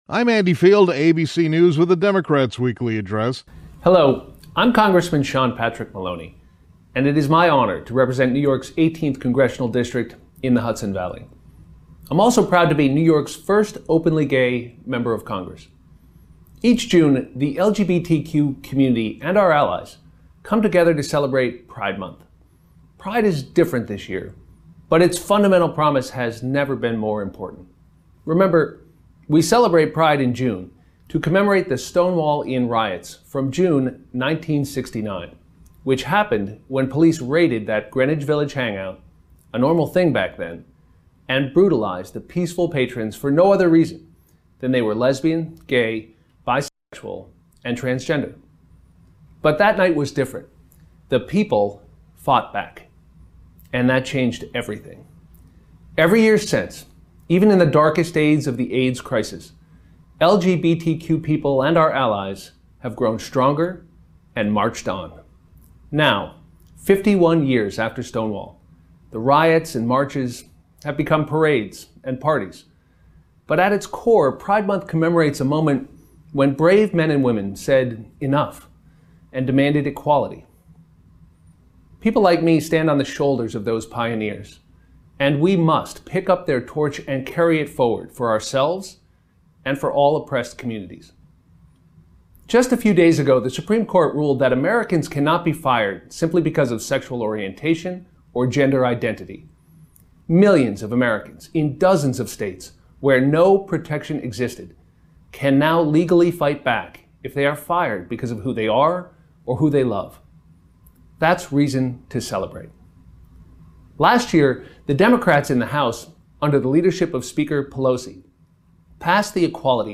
During the Democratic Weekly Address, Rep. Sean Patrick Maloney (D-NY) celebrated Pride Month and stated that the Equality Act still needs to be passed by the Senate.